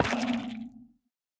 Minecraft Version Minecraft Version snapshot Latest Release | Latest Snapshot snapshot / assets / minecraft / sounds / block / sculk_shrieker / place1.ogg Compare With Compare With Latest Release | Latest Snapshot